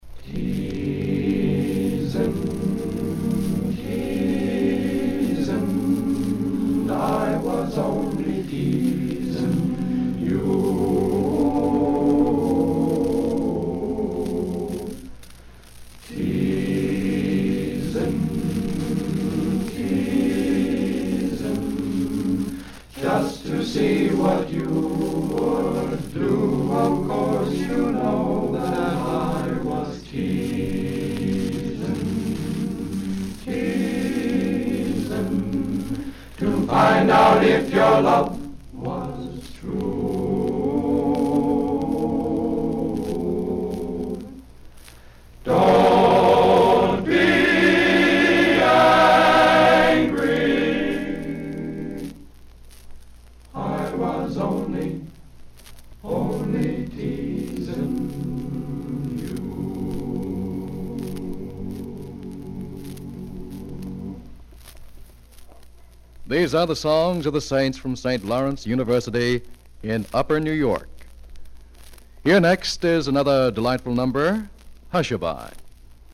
with very minor restoration